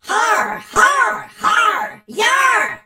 darryl_kill_vo_02.ogg